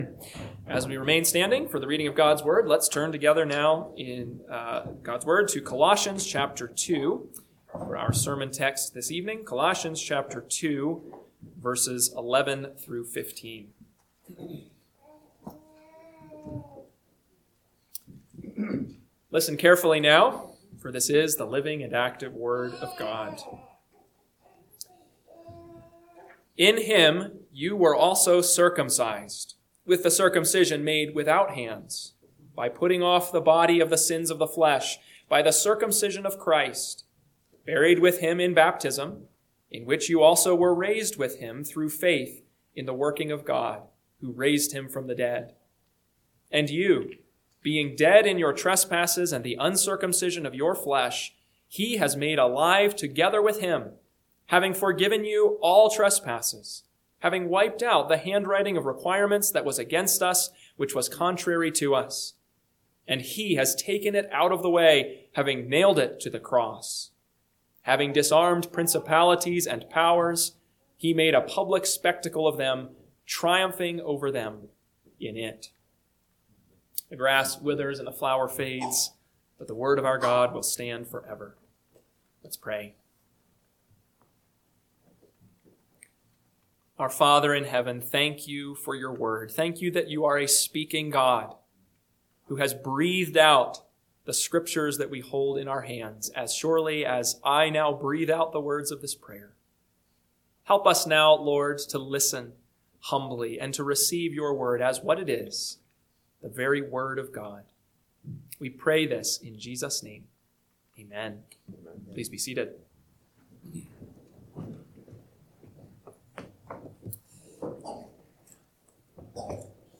PM Sermon – 3/1/2026 – Colossians 2:11-15 – Northwoods Sermons